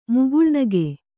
♪ muguḷnage